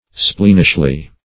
-- Spleen"ish*ly , adv.